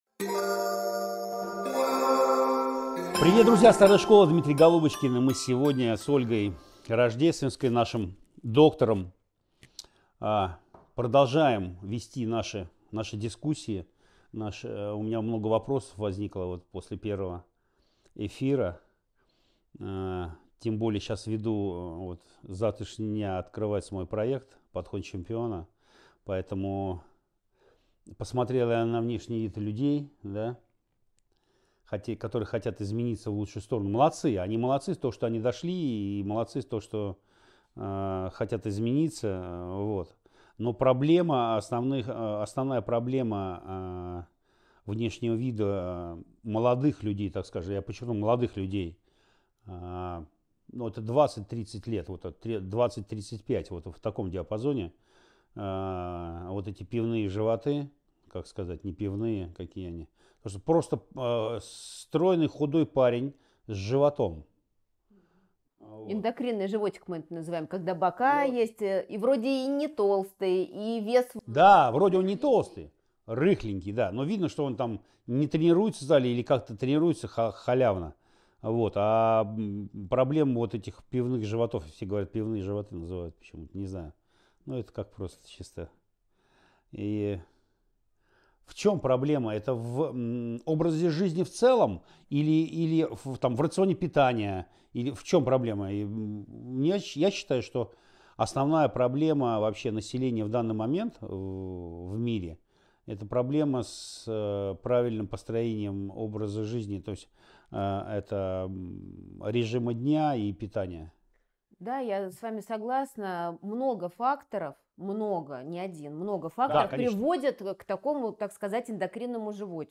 Старая школа. Второй эфир. Беседа про гормоны, жир и пивные животы.
Источник: Персональное интервью